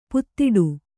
♪ puttiḍu